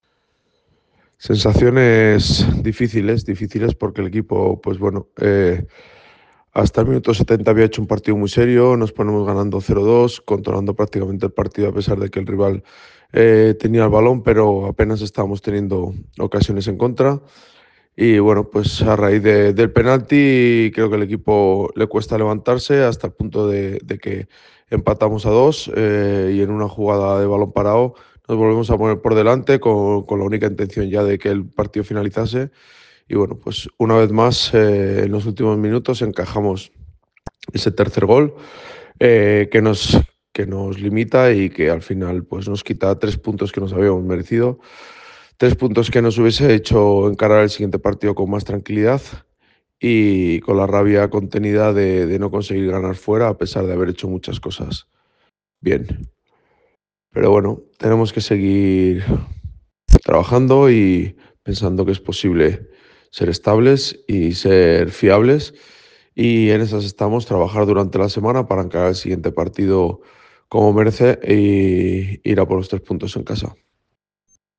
Ruedas de prensa